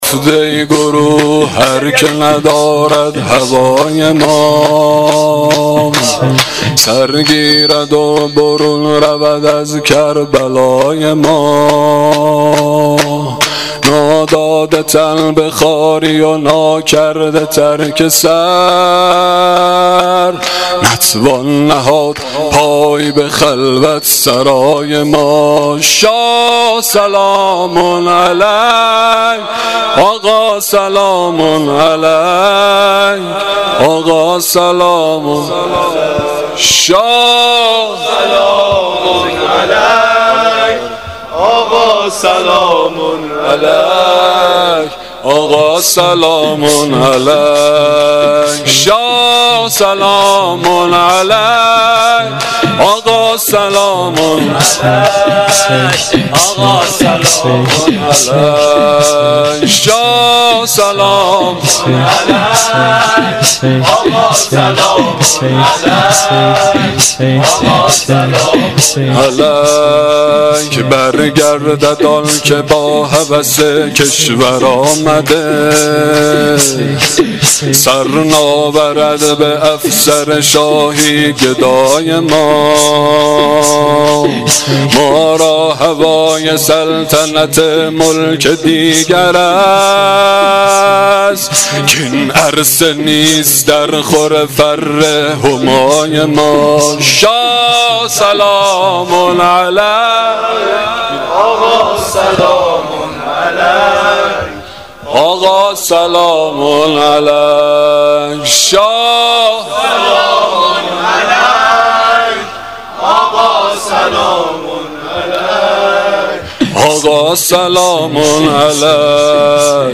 شور شب ششم محرم الحرام 1396
• Shabe06 Moharram1396[08]-Shoor.mp3